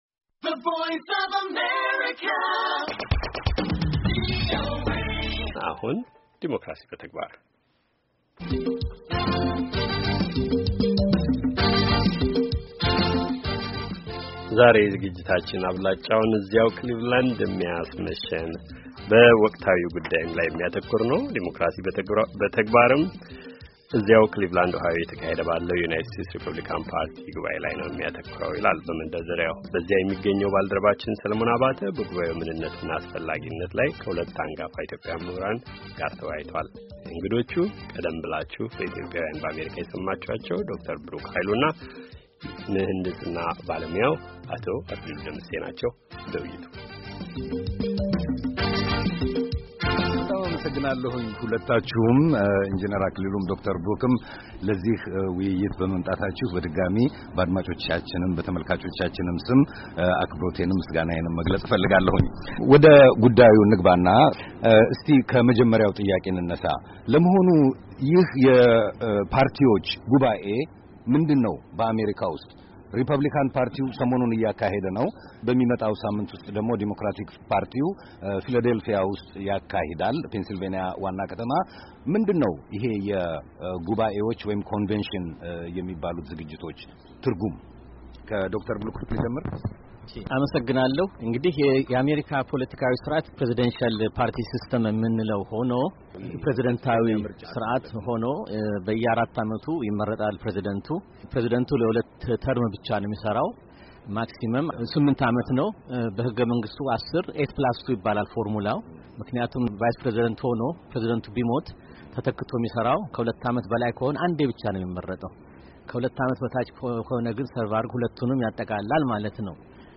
የሬፖብሊካን ጉባዔ በተመለከተ ከኢትዮጵያዊያን ምሁራን ጋር የተካሄደ ውይይት